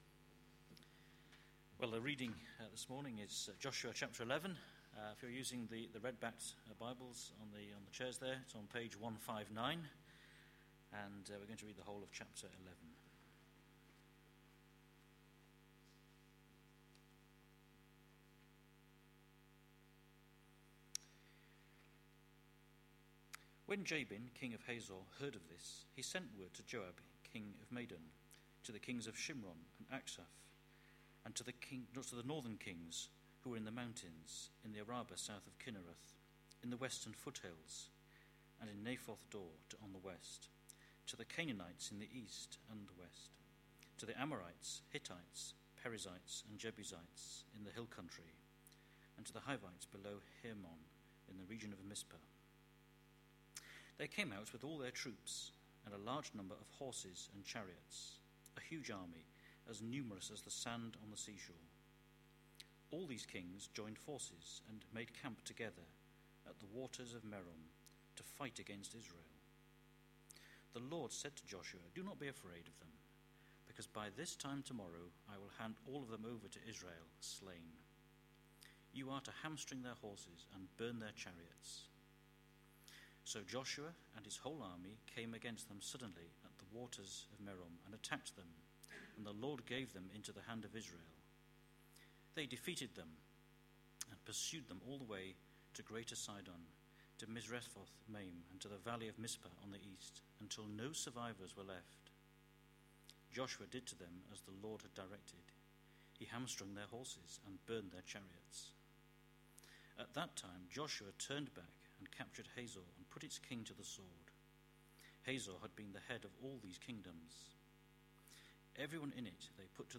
A sermon preached on 24th June, 2012, as part of our Entering God's Rest series.